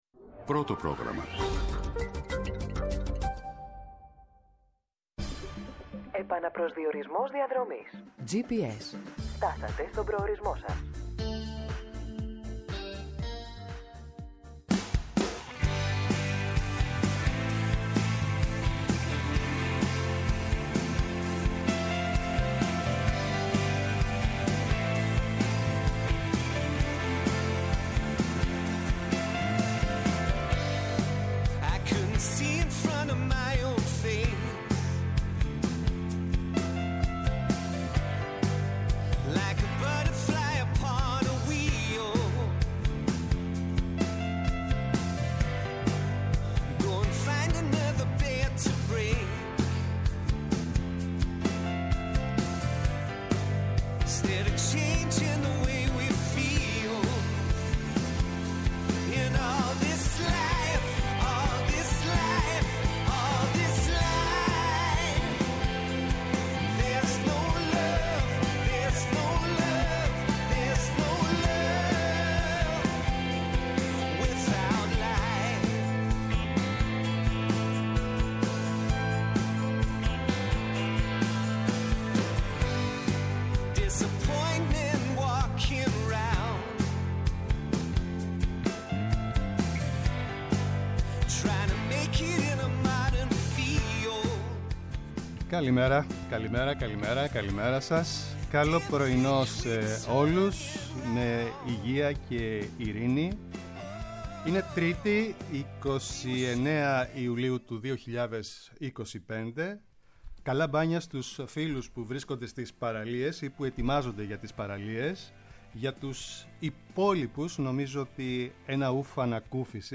-O Χρήστος Κέλλας, Υφυπουργός Αγροτικής Ανάπτυξης, για τους δασμούς που ανακοίνωσε ο Αμερικανός Πρόεδρος και την συζήτηση της πρότασης της ΝΔ για Εξεταστική σχετικά με τον ΟΠΕΚΕΠΕ
-Η Μιλένα Αποστολάκη, Βουλευτής ΠΑΣΟΚ, για την πολιτική επικαιρότητα